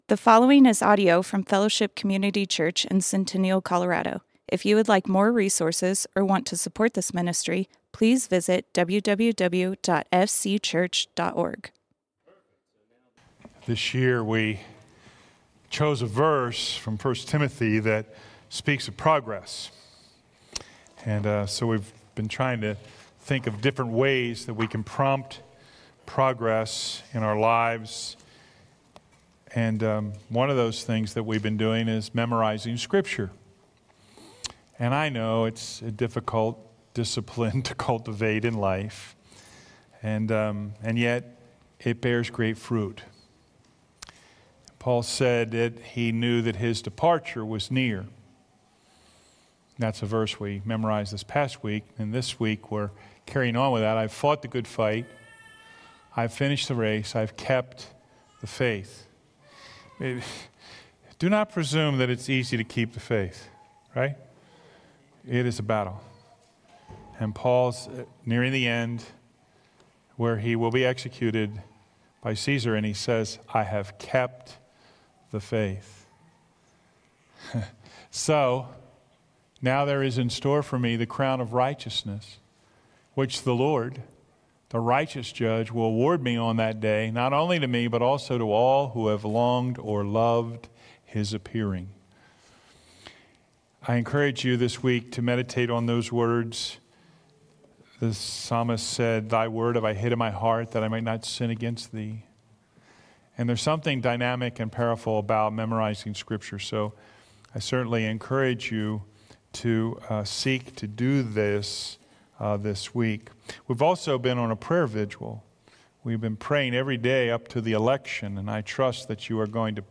Fellowship Community Church - Sermons Question Authority?